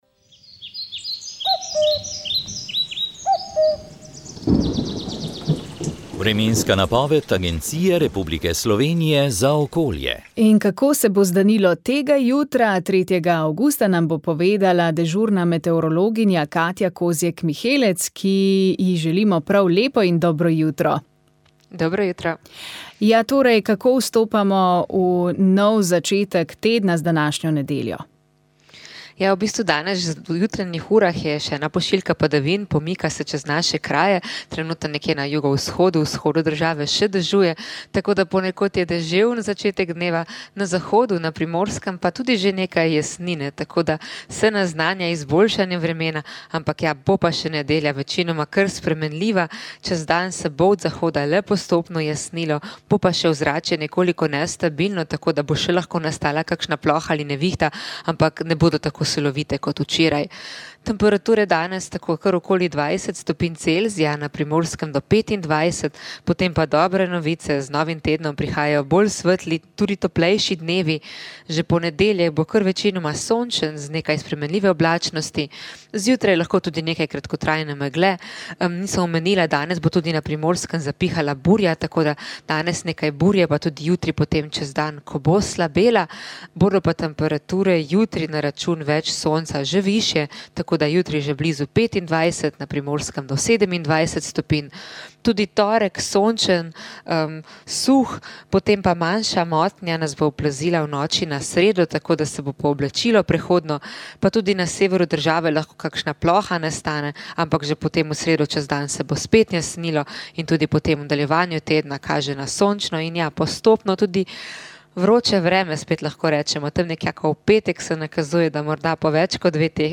Predstavili smo nekatere župnije in tamkajšnje versko življenje. Ob Dnevu za Magnificat, ki smo ga pripravili na Radiu Ognjišče, so molivci spregovorili o zanimivostih v teh župnijah ter predstavili skupine, ki v njih delujejo.